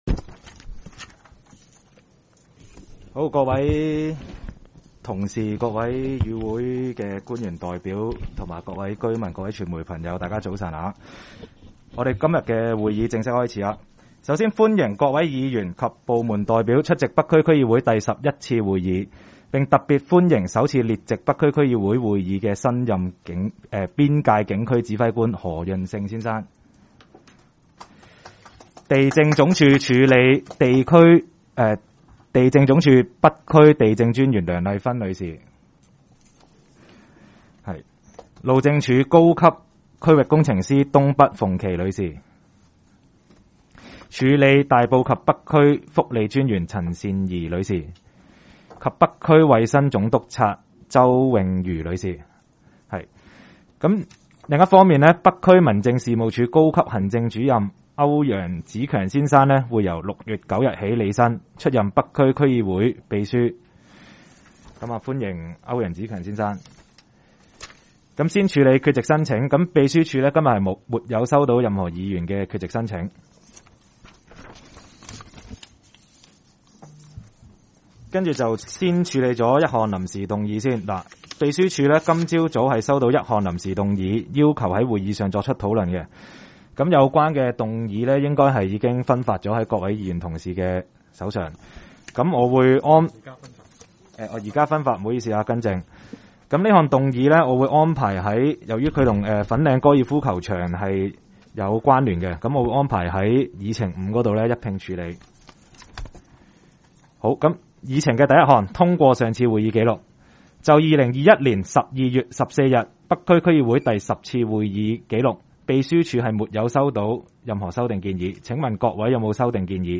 区议会大会的录音记录
北区区议会第十一次会议
北区民政事务处会议室